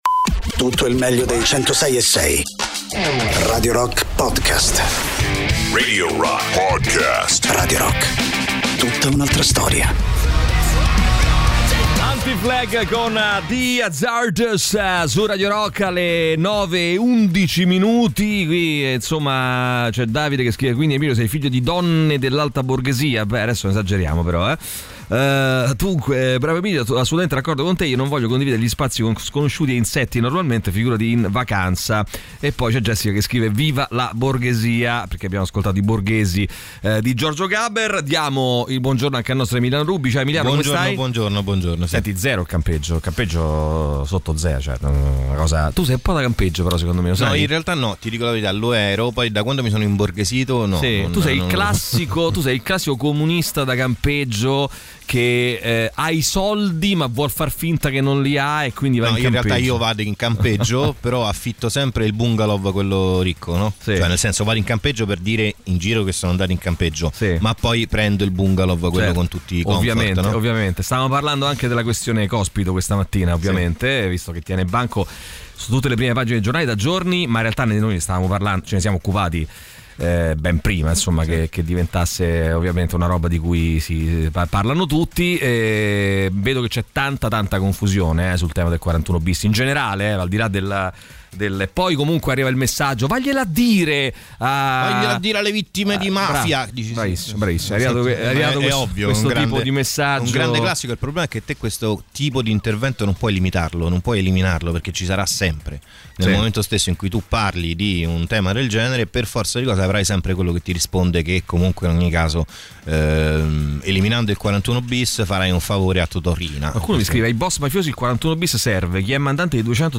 Interviste
ospiti in studio